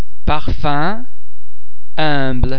un um vowel base similar to ir in [sir]
The French [un] nasal vowel sound is made up of the [ oe ] vowel base which is subsequently nasalised by the air being passed through the mouth and the nostrils at the same time.